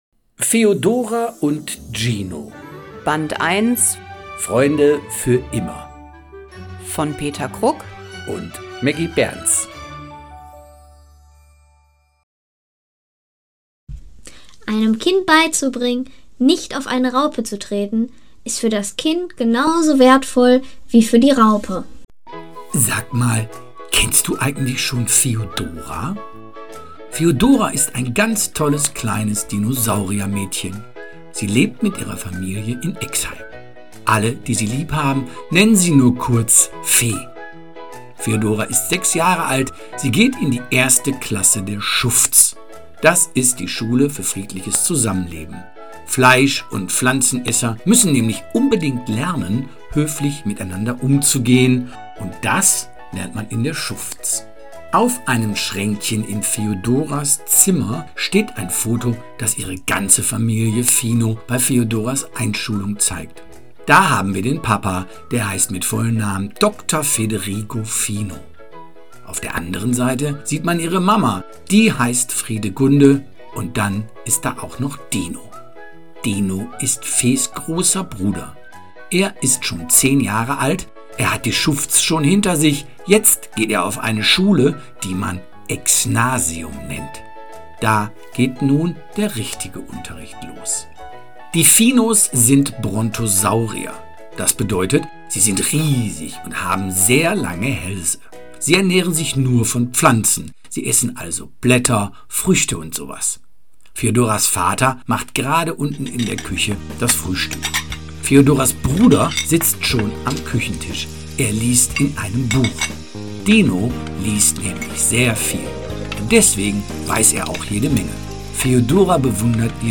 Mit liebevoll eingesprochenen Dialogen, atmosphärischer Musik und lebendigen Geräuschen wird die Geschichte zum echten Hörerlebnis – perfekt zum Einschlafen, Entspannen oder einfach zum Genießen.
Hochwertige Sprecher & stimmungsvolle Geräuschkulisse